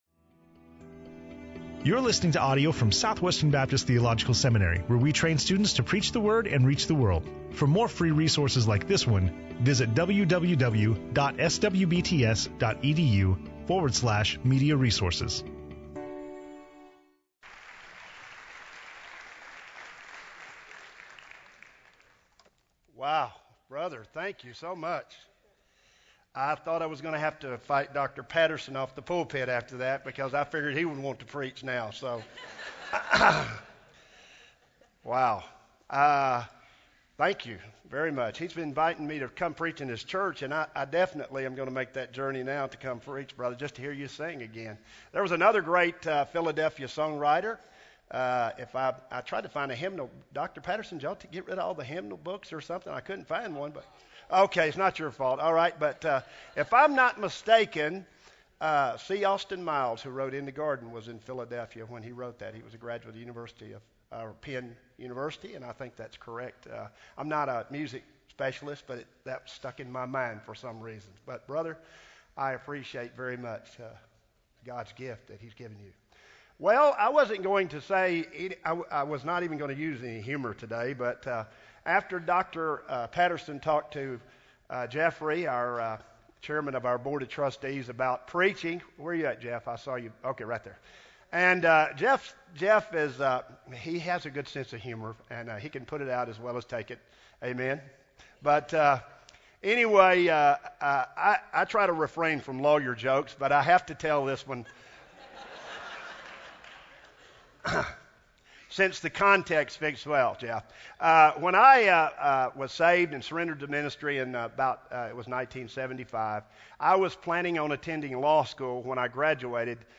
in SWBTS Chapel on Wednesday October 20, 2010